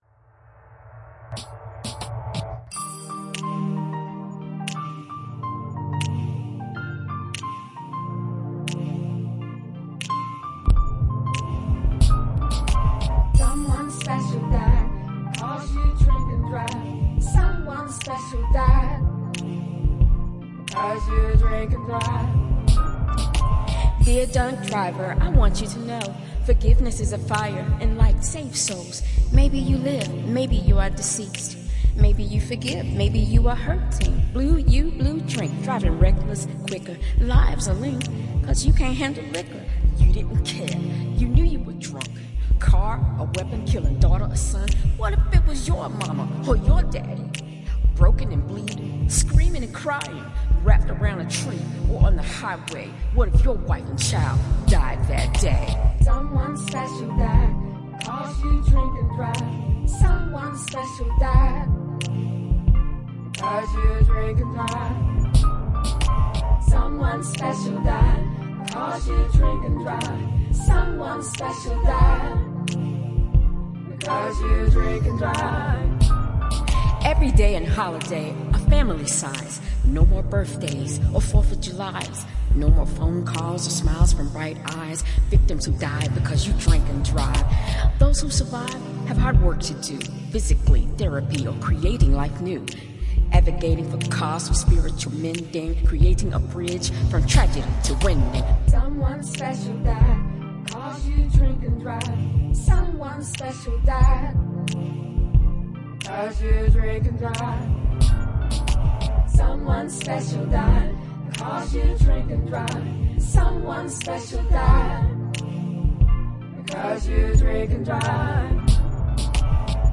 This sounds so good with music.
I really enjoyed the tune and voices.
I could not afford anyone so I sang it myself.